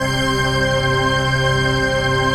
DM PAD2-71.wav